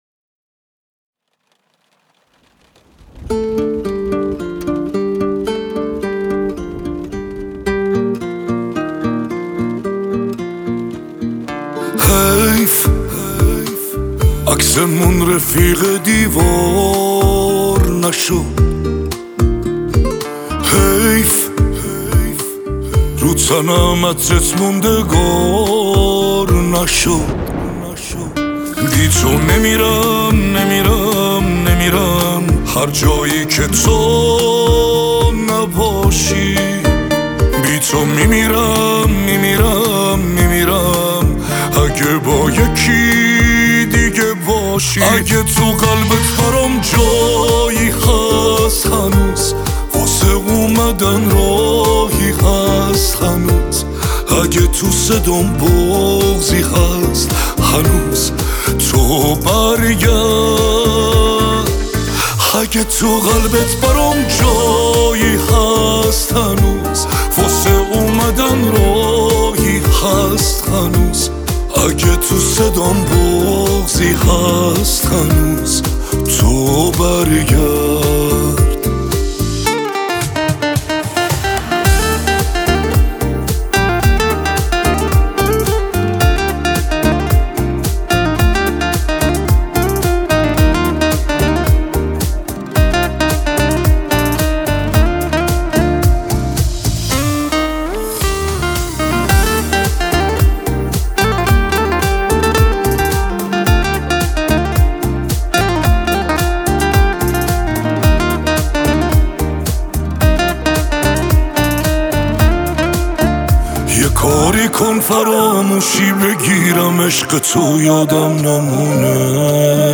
سبک پاپ